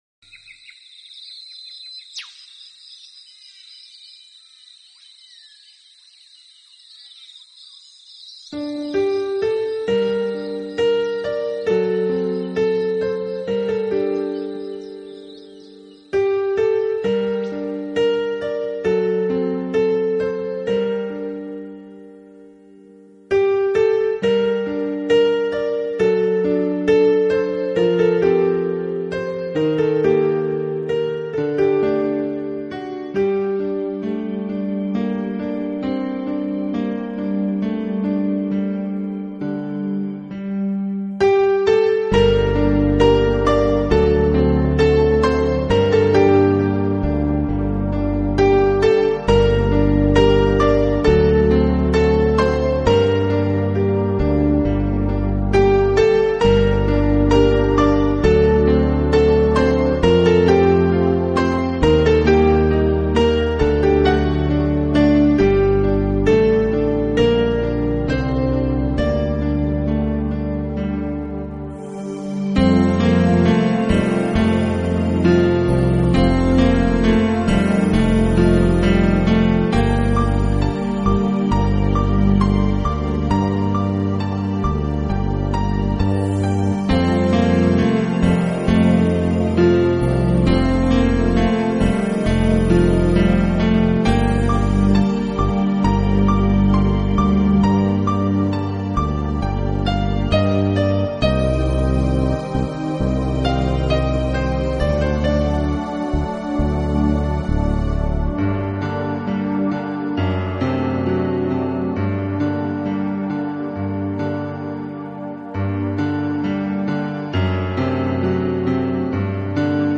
2007年浪漫温馨、清新典雅的大自然声音专辑
Style: Meditative, Relax